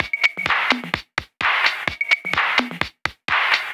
VEH1 Fx Loops 128 BPM
VEH1 FX Loop - 04.wav